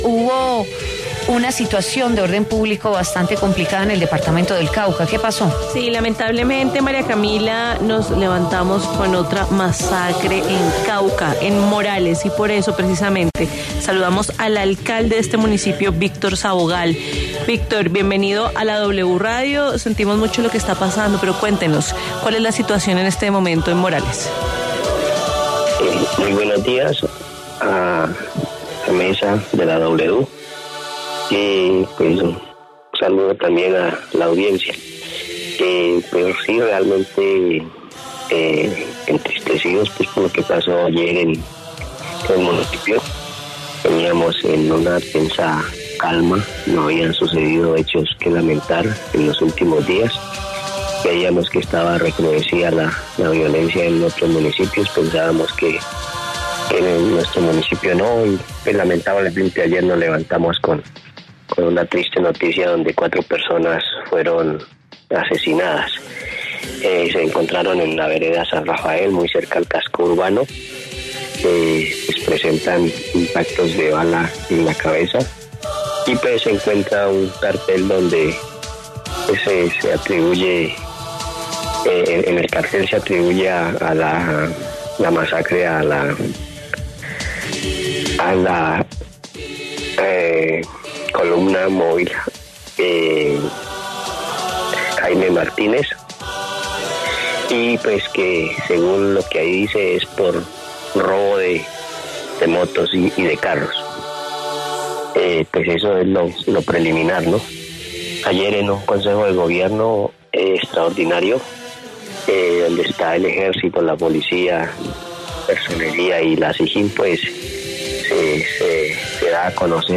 Víctor Sabogal, alcalde de Morales, Cauca, habló en W Fin de Semana sobre el hecho en el que murieron cuatro personas.